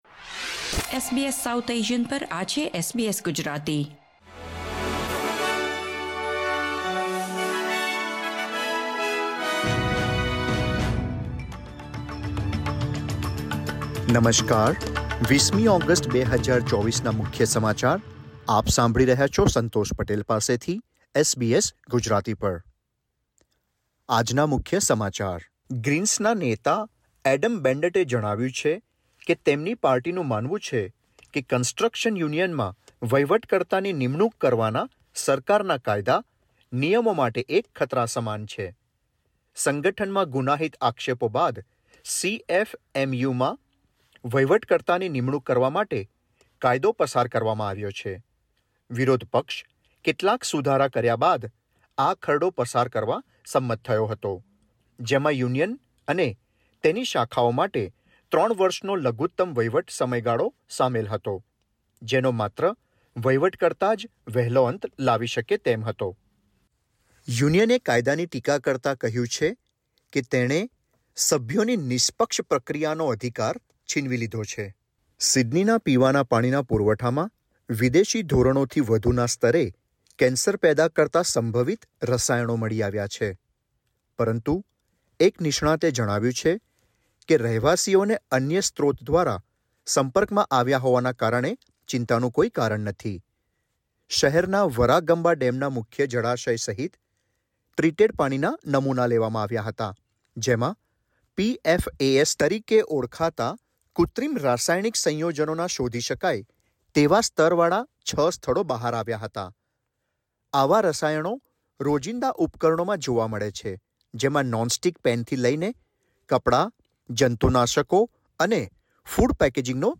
SBS Gujarati News Bulletin 20 August 2024